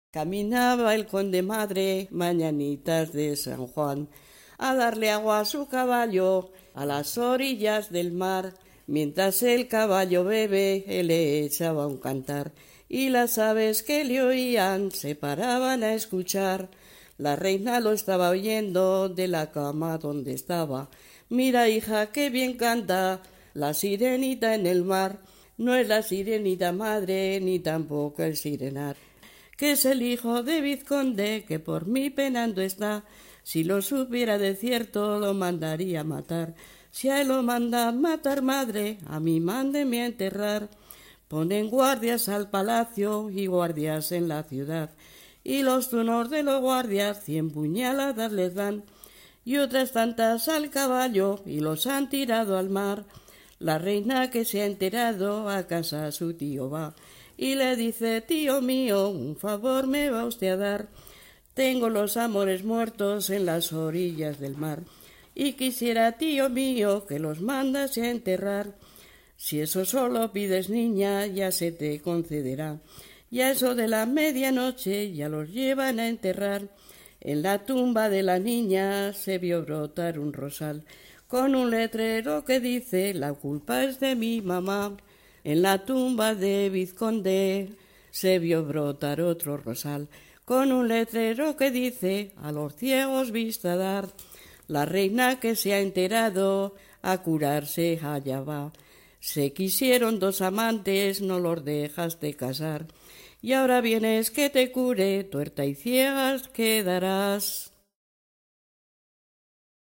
Clasificación: Romancero
Localidad: Avellaneda de Cameros
Lugar y fecha de recogida: Logroño, 15 de junio de 2001